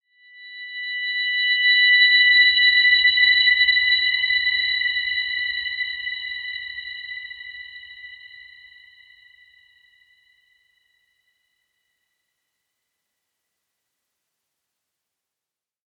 Dreamy-Fifths-B6-p.wav